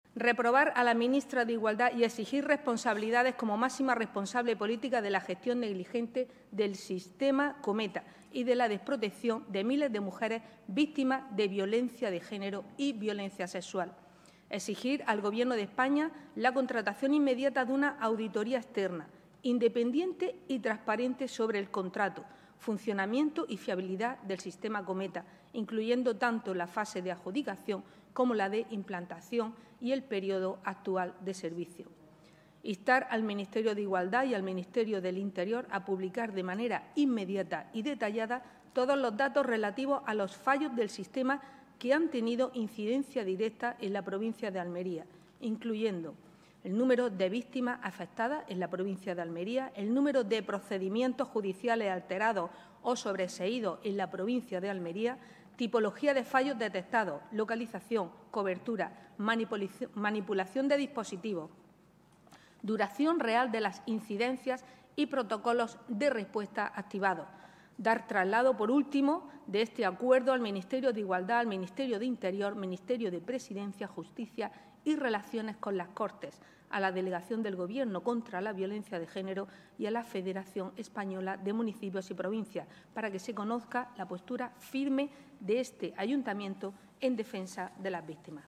La moción, defendida por la concejala de Familia, Paola Laynez y aprobada con los votos favorables de PP y Vox y que ha encontrado el voto en contra de Psoe y Podemos, se enmarca dentro de la iniciativa promovida por el Partido Popular en todos los ayuntamientos de España, con el objetivo de reclamar transparencia, depuración de responsabilidades – de forma particular en la persona de la ministra de Igualdad, Ana Redondo, a la que se ha pedido su dimisión -, y garantías de seguridad para las mujeres víctimas de violencia machista.